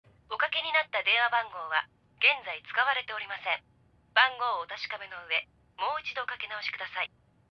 Звук неправильно набраний номер автоответчик говорить японською мовою